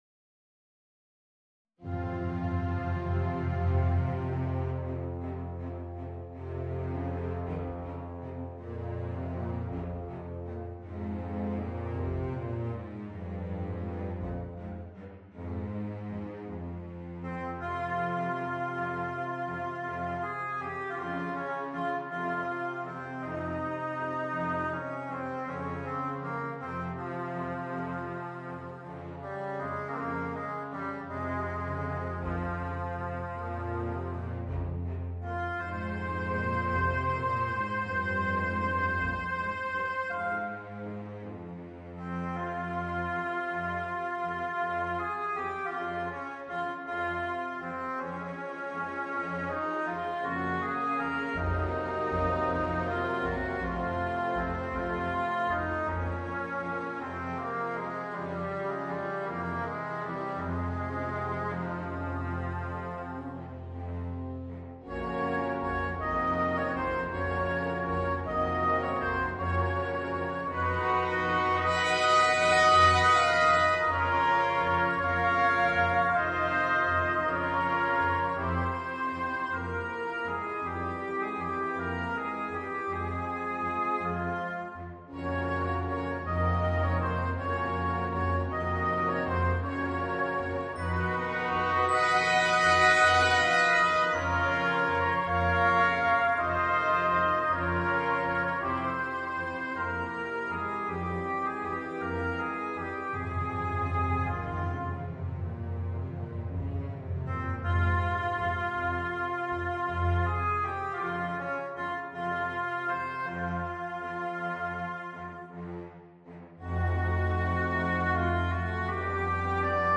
Voicing: Alto Saxophone and Orchestra